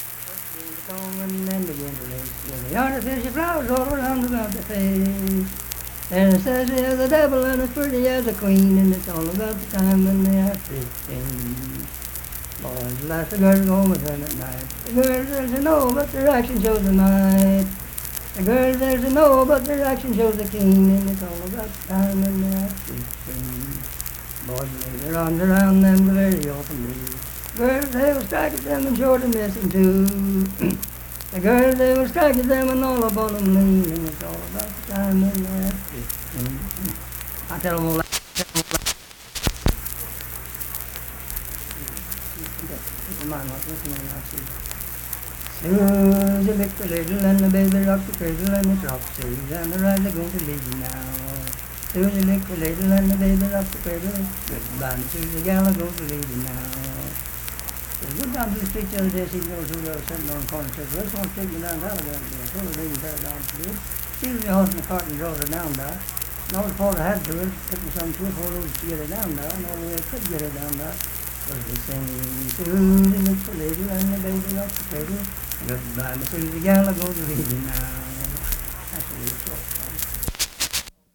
Unaccompanied vocal music
Verse-refrain, 3(6).
Voice (sung)
Lincoln County (W. Va.), Harts (W. Va.)